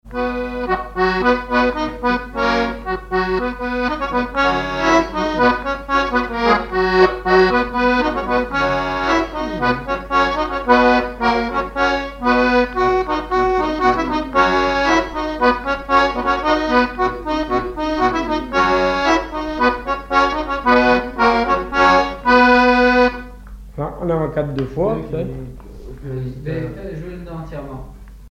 Saint-Hilaire-de-Riez
danse : quadrille : avant-quatre
accordéon diatonique
Pièce musicale inédite